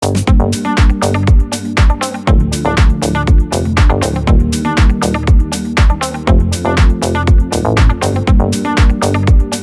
Нужна помощь в поиске гитары